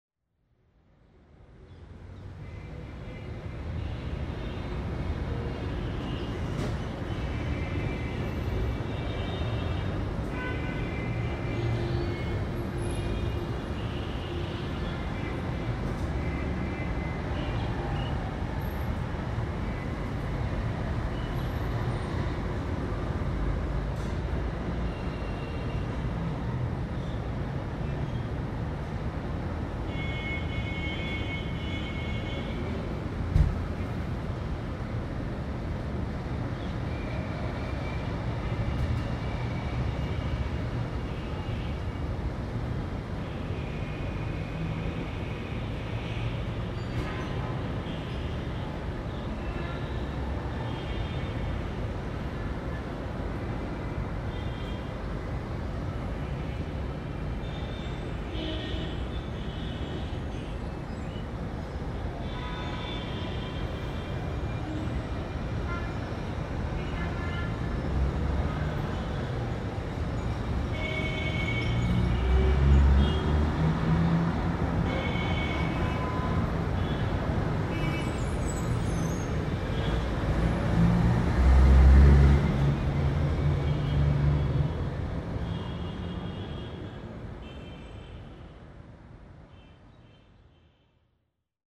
Soundcities, Delhi Traffic1:32
hildegard_westerkamp_-_05_-_delhi_traffic.mp3